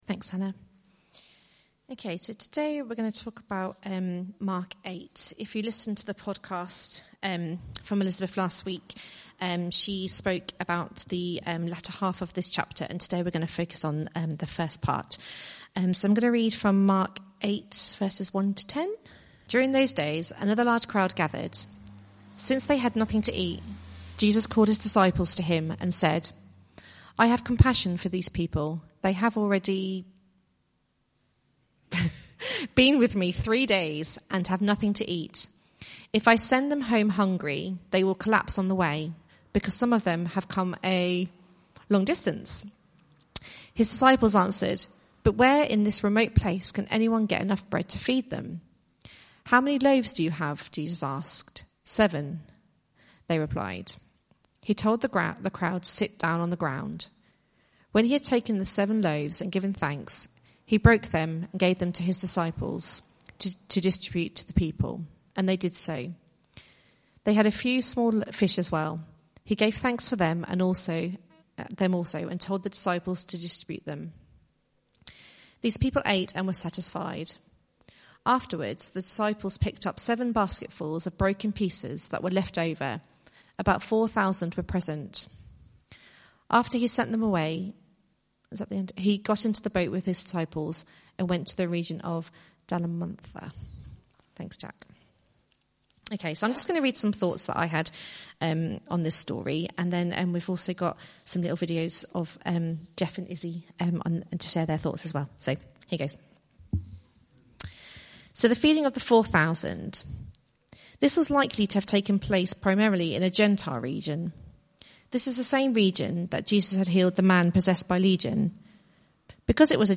Mark Service Type: Sunday Morning Preacher